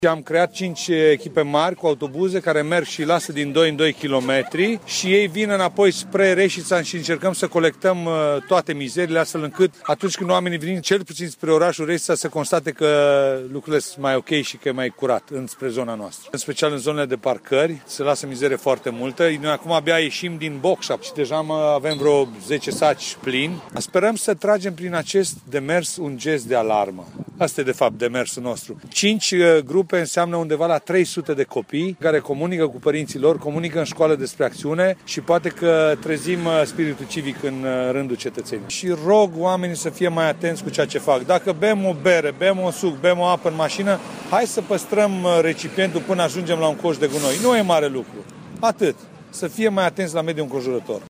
Interviu-primar-Resita-curata.mp3